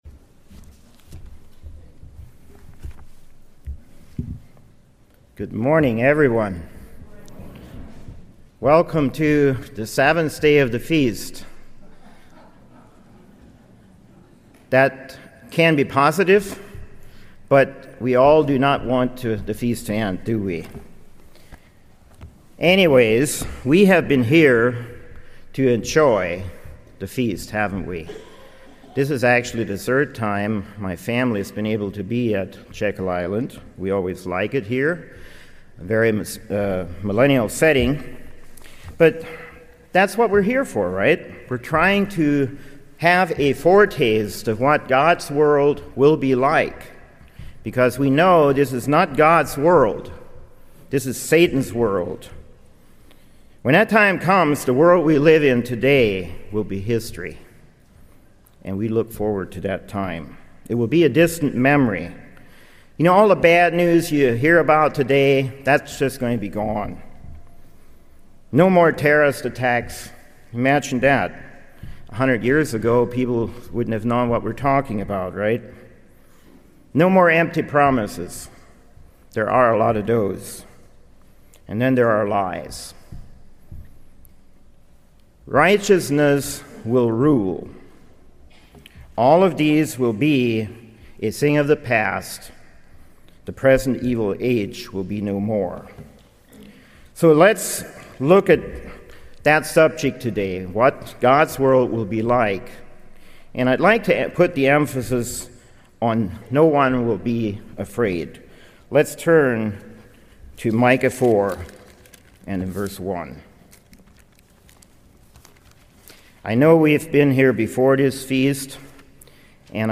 This sermon was given at the Jekyll Island, Georgia 2019 Feast site.